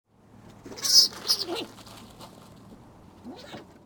Звуки скунса
Рычание скунса вблизи